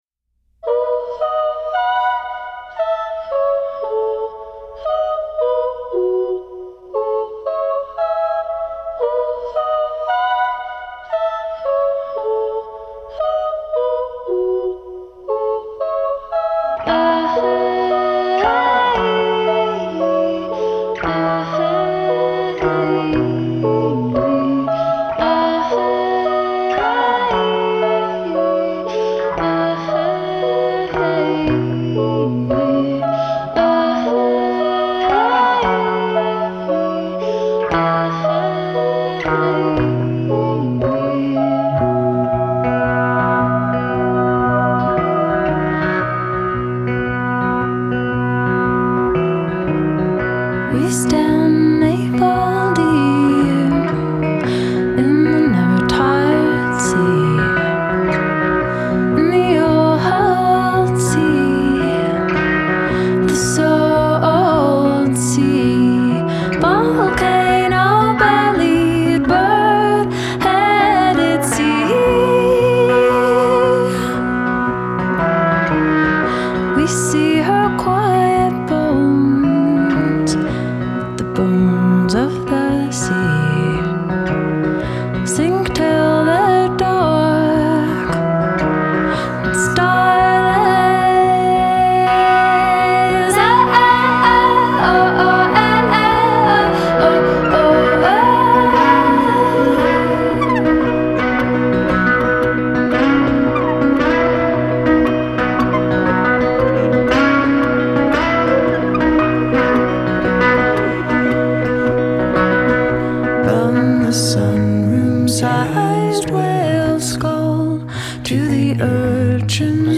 cinematically sensuous sophomore album
you’ll hear beautiful compositions
spectral vocals
stringed instruments
winningly-unique arrangements